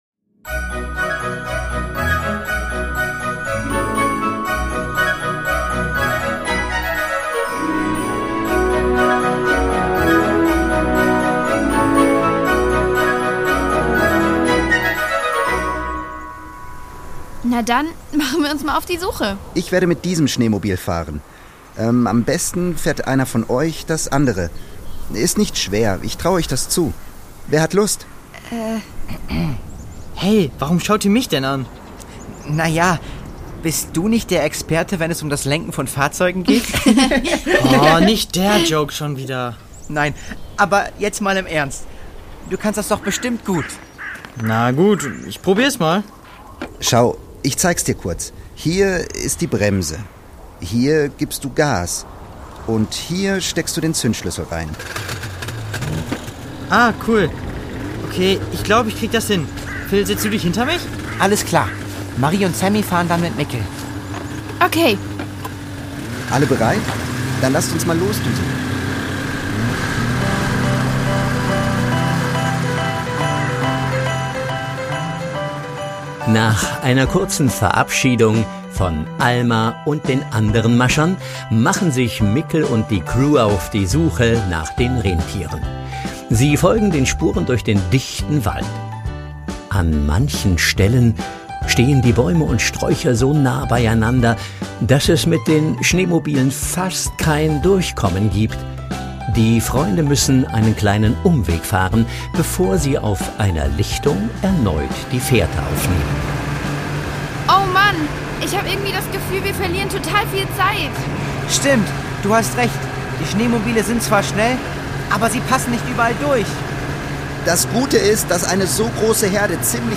Die Doppeldecker Crew | Hörspiel für Kinder (Hörbuch)